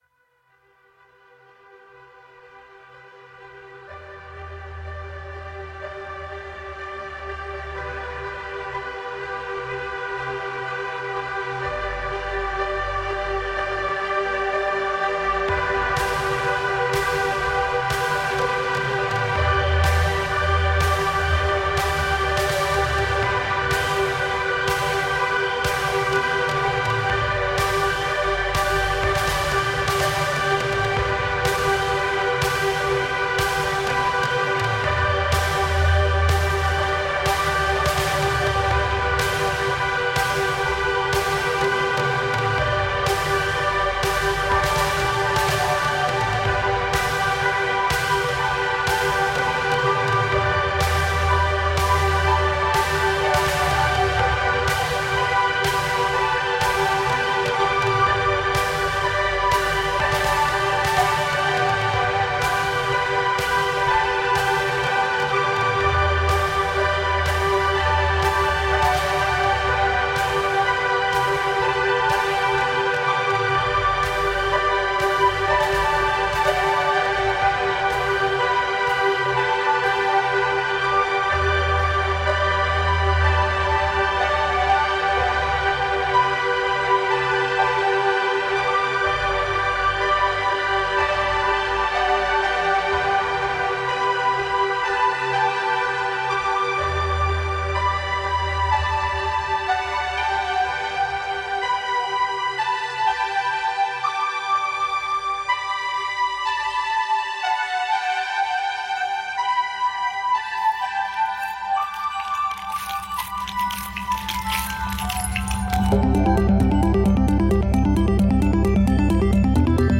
Intriguing ambient music telling tales from south africa.